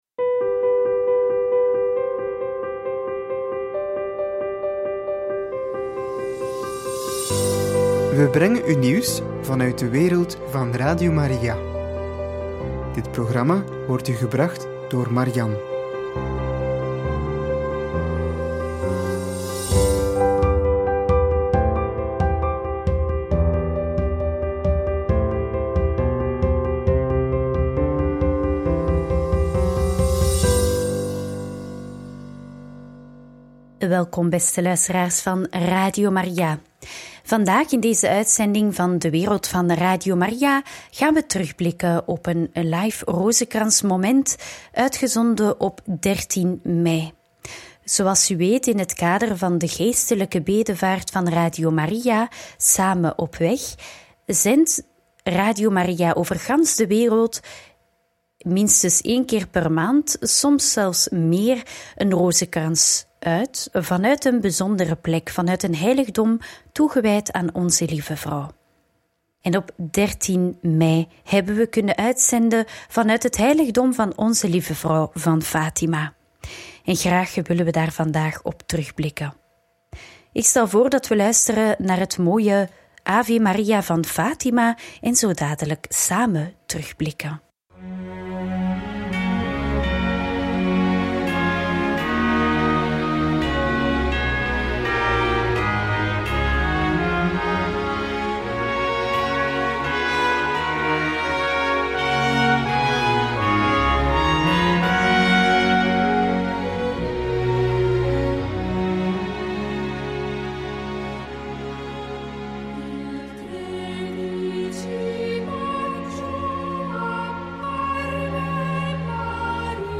Terugblik op de live rozenkrans vanuit Fatima op 13 mei 2024 – Radio Maria